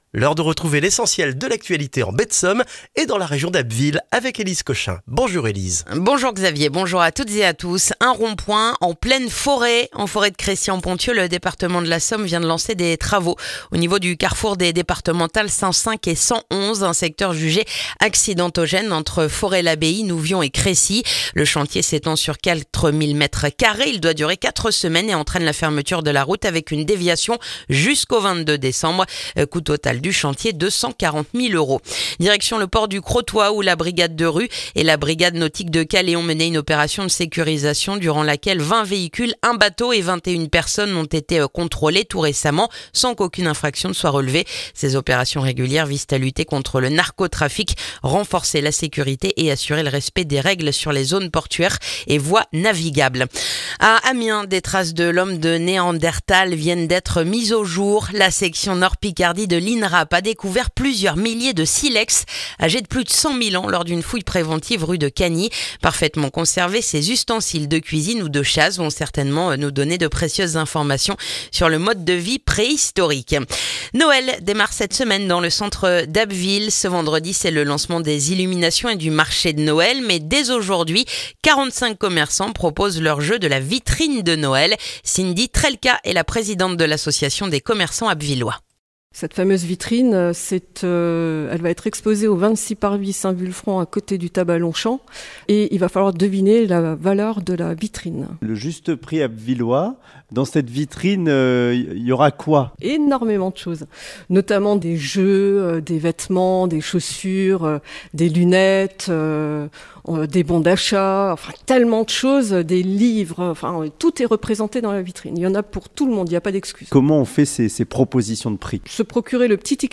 Le journal du lundi 1er décembre en Baie de Somme et dans la région d'Abbeville